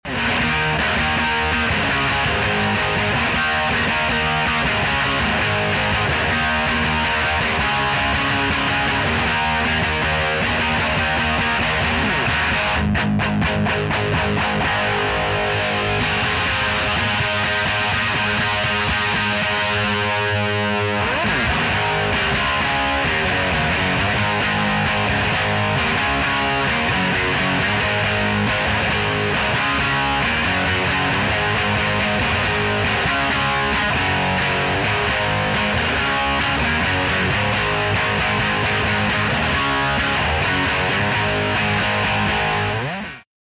The MXR Dookie Drive Pedal captures the dirty yet articulate sound Billie Joe Armstrong used on Green Day's iconic Dookie record, combining the sounds of two modded amplifiers into a single stomp box for a harmonic range full of depth and dimension.